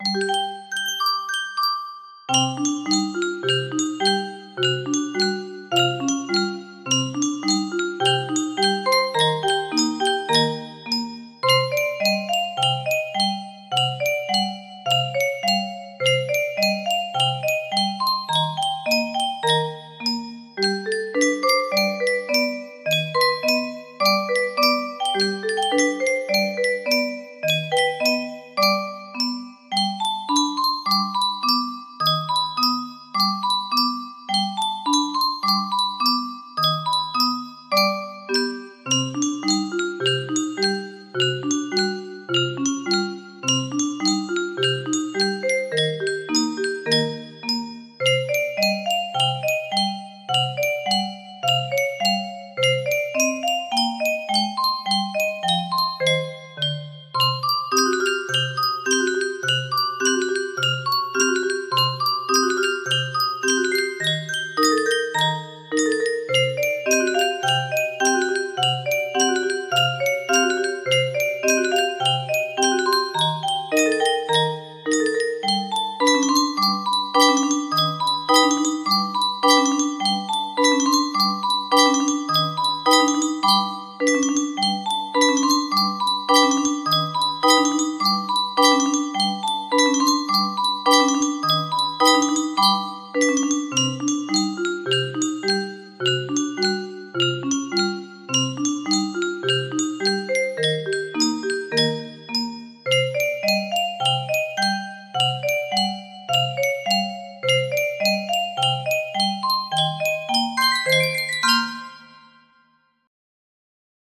In the Hall of the Mountain King music box melody
Full range 60